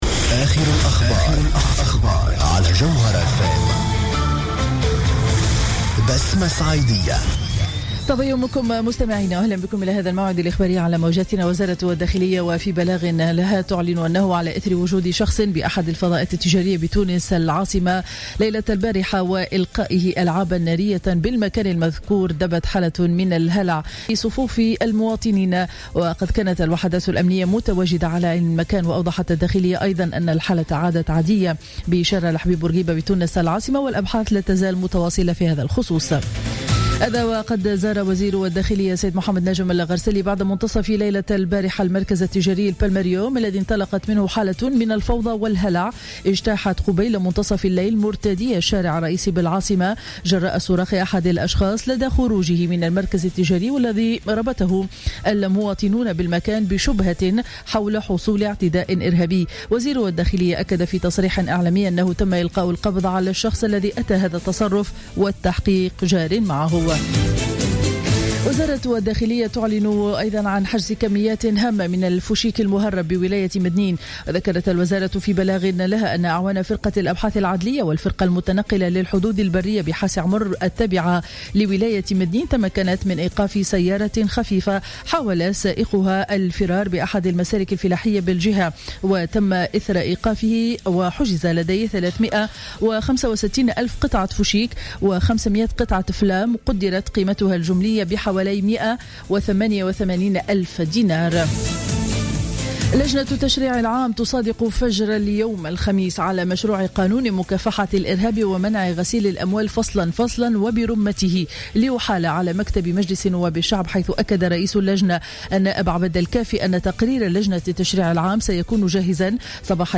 نشرة أخبار السابعة صباحا ليوم الخميس 16 جويلية 2015